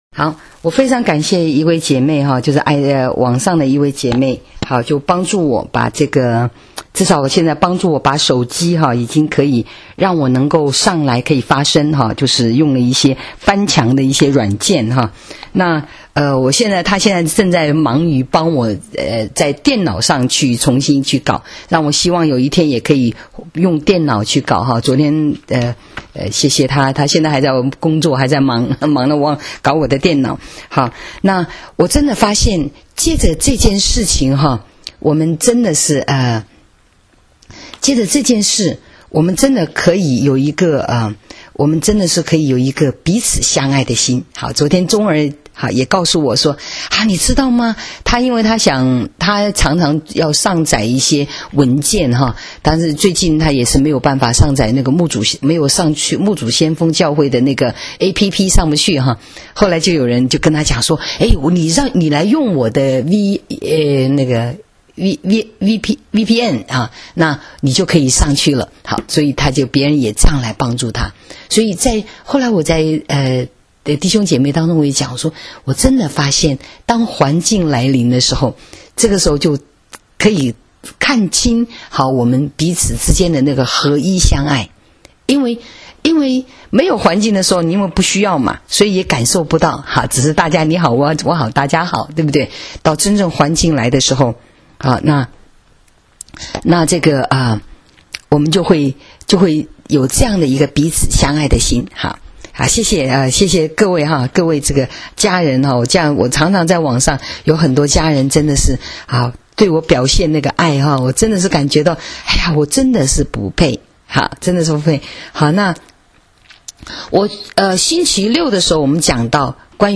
【主日信息】恢复起初的爱心（2） （6-2-19）